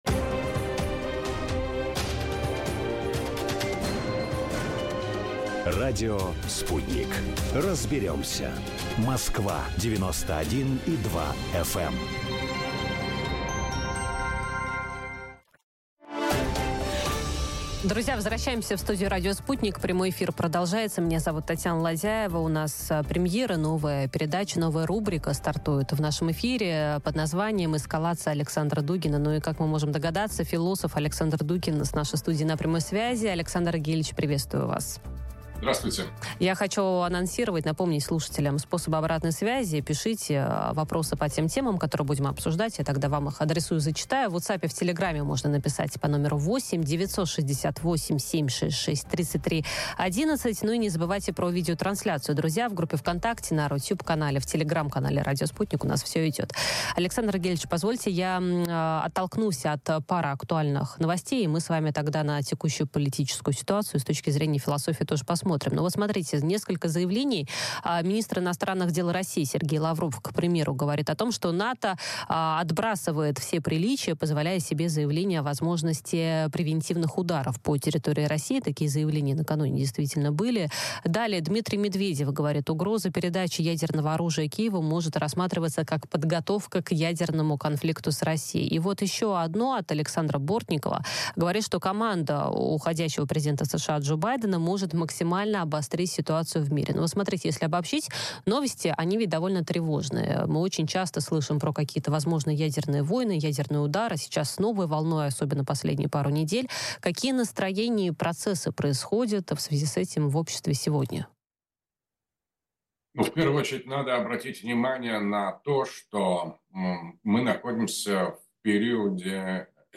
Ответы на эти и другие вопросы ищем в эфире радио Sputnik с философом Александром Дугиным.